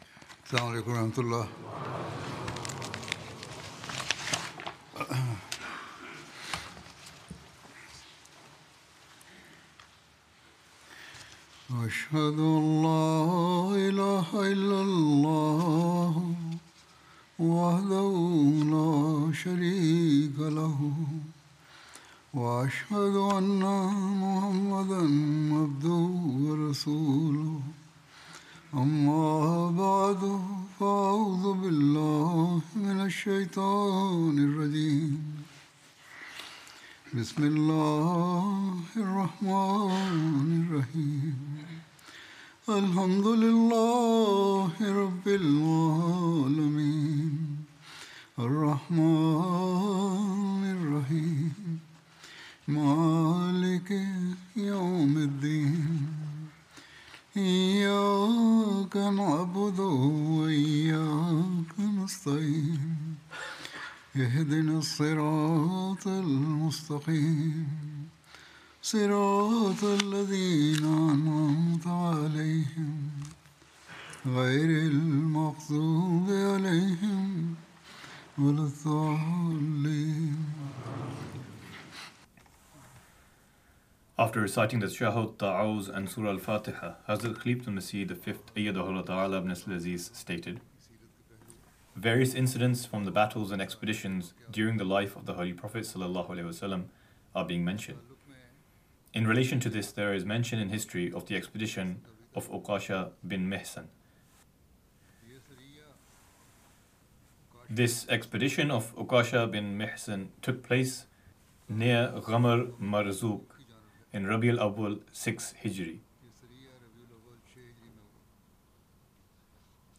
English Translation of Friday Sermon delivered by Khalifatul Masih